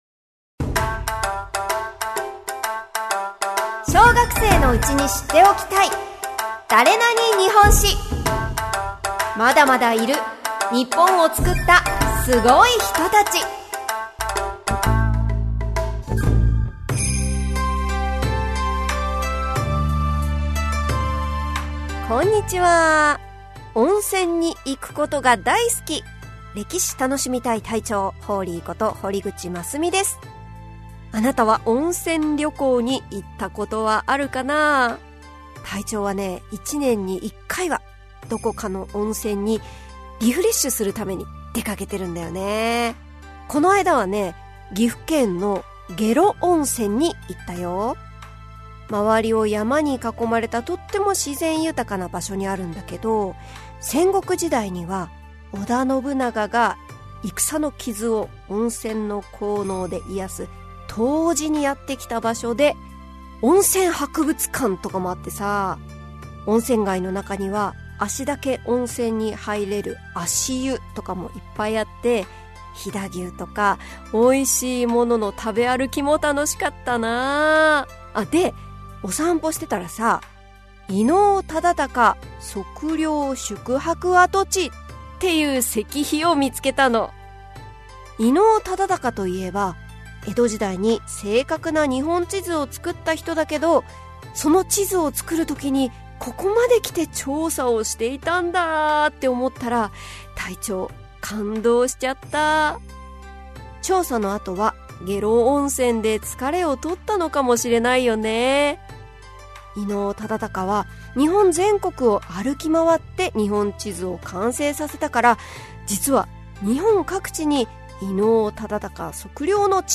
[オーディオブック] 小学生のうちに知っておきたい！だれなに？日本史 Vol.13 〜伊能忠敬〜